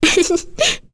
Kirze-Vox_Happy1.wav